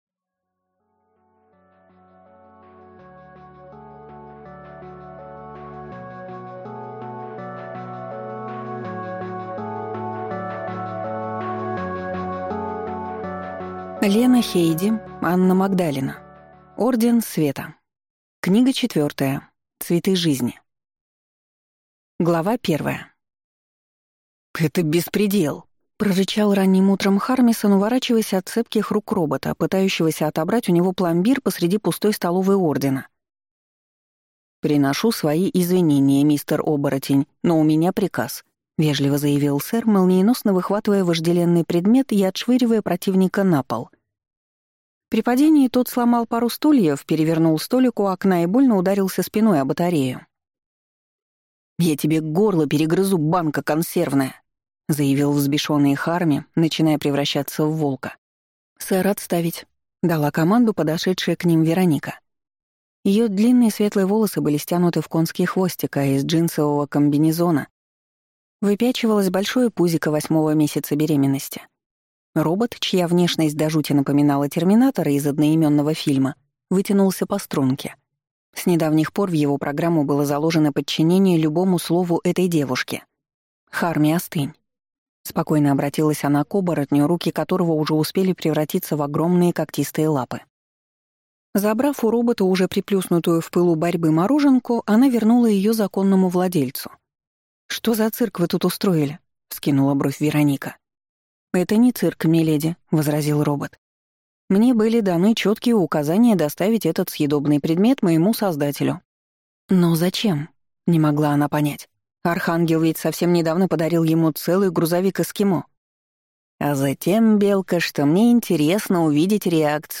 Аудиокнига Орден Света. Цветы жизни | Библиотека аудиокниг